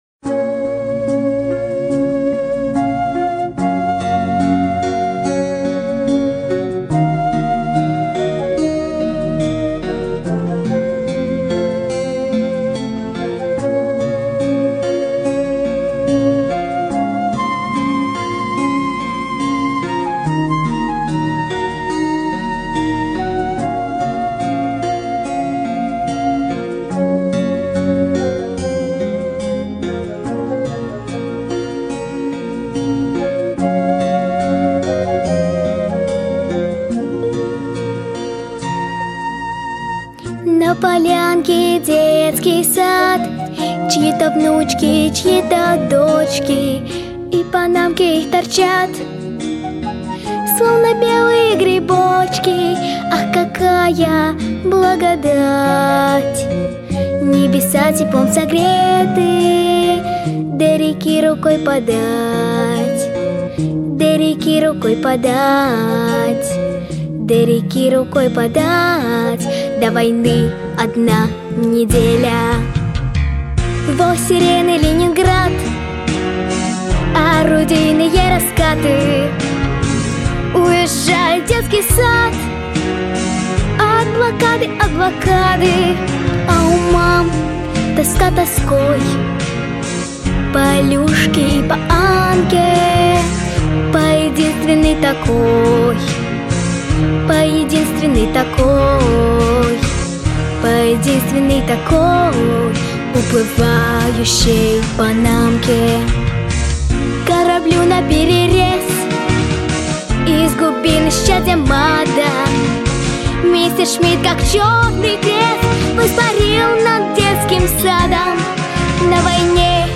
• Качество: Хорошее
• Жанр: Детские песни
военные песни